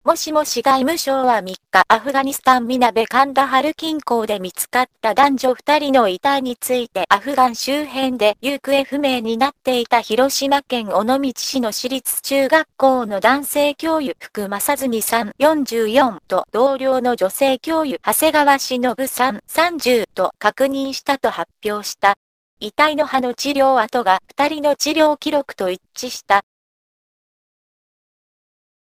Texte de d�monstration lu par Kyoko (Nuance RealSpeak; distribu� sur le site de Nextup Technology; femme; japonais)